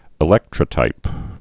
(ĭ-lĕktrə-tīp)